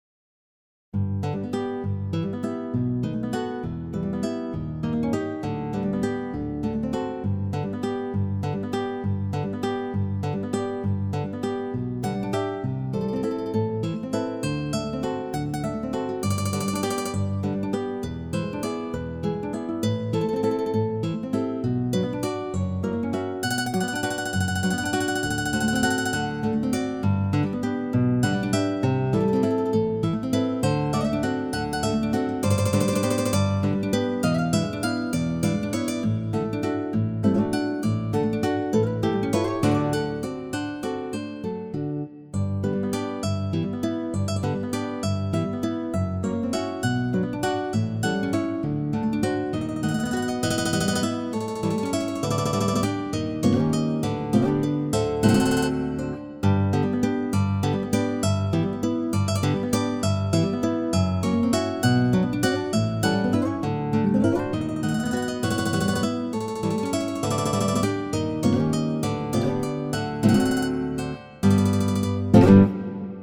arrangiert für Zupforchester
ist eine wunderbare Canzone, arrangiert für Zupforchester